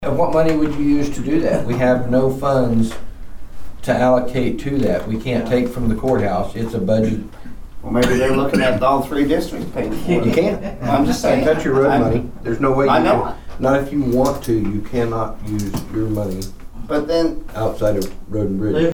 The Nowata County Commissioners met for the first time in 2026 on Monday morning at the Nowata County Annex.
Member Troy Friddle talked about a lack of funds for the county, despite receiving concerns regarding the strength of signal for radios.